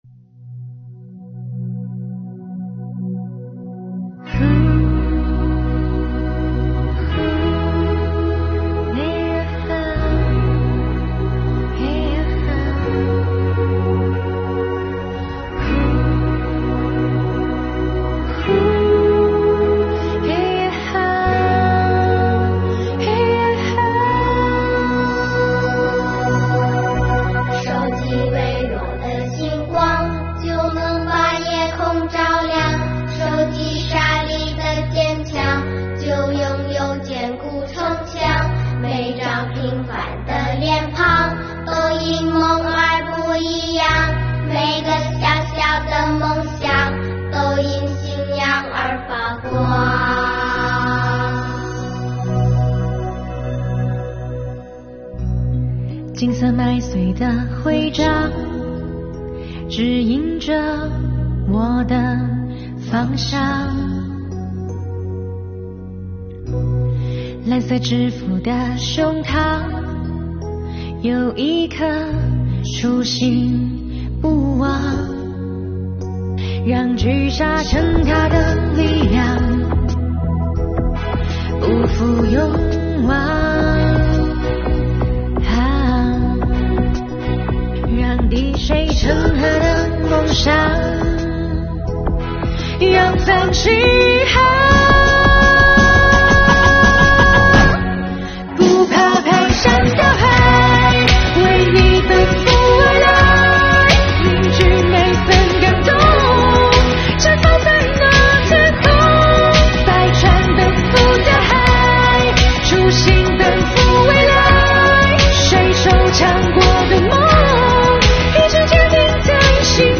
一首饱含深情的歌曲唱出了梧州税务人不忘初心、兴税强国的铮铮誓言。